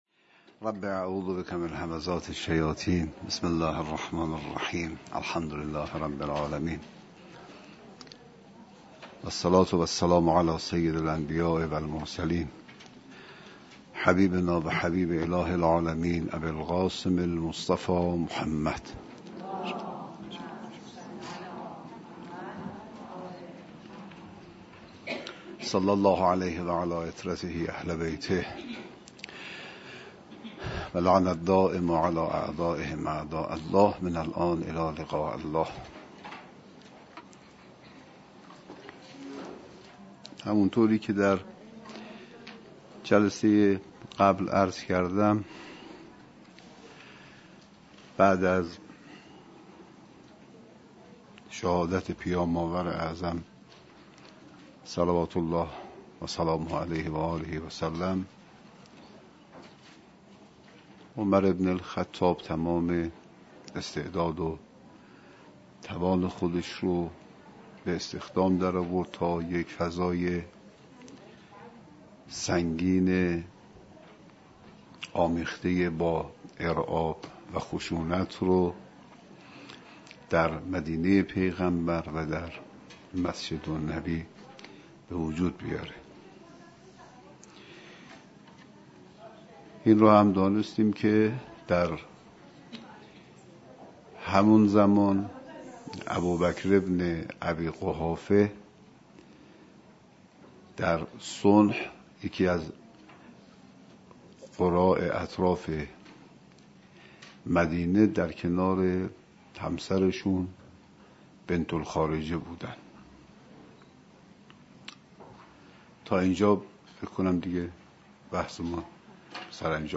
108 - تلاوت قرآن کریم